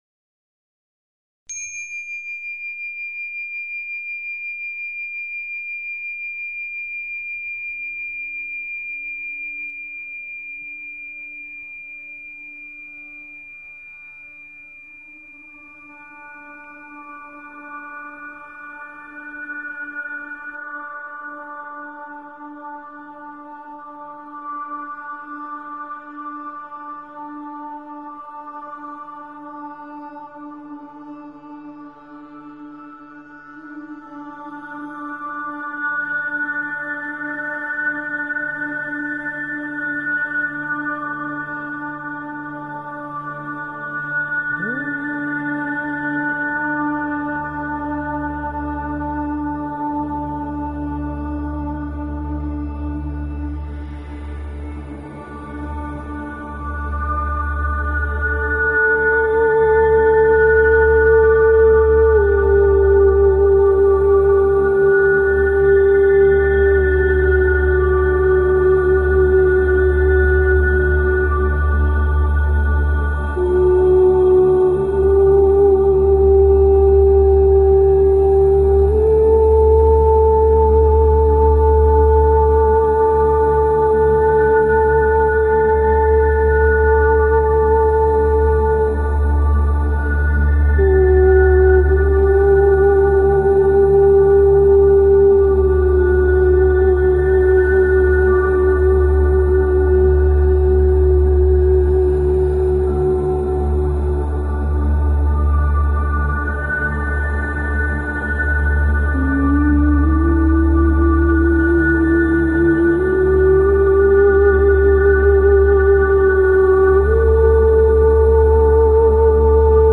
Talk Show Episode, Audio Podcast, Radiance_by_Design and Courtesy of BBS Radio on , show guests , about , categorized as
During the show, callers (and listeners) can feel the transformative energy directly through the airwaves.